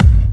VEC3 Bassdrums Trance 64.wav